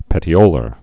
(pĕtē-ōlər)